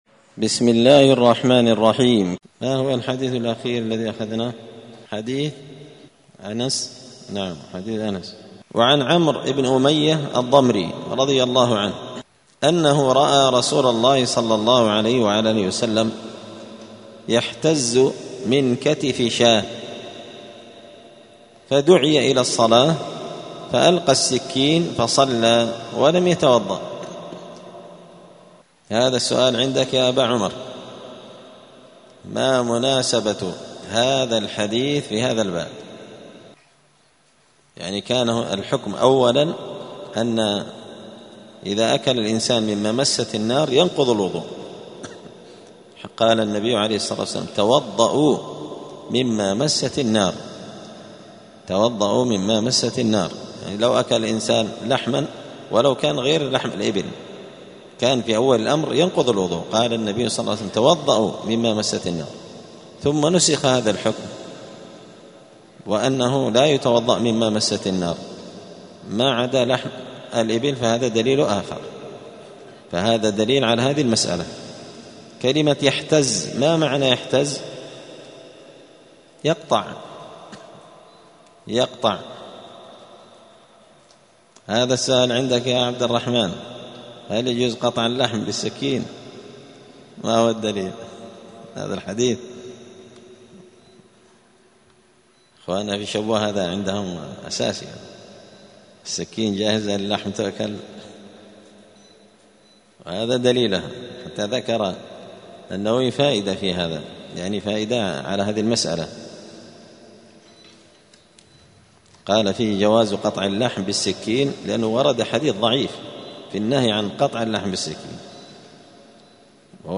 دار الحديث السلفية بمسجد الفرقان قشن المهرة اليمن
*الدرس الحادي والخمسون [51] {باب ما ينقض الوضوء هل يتوضأ من ما مست النار}*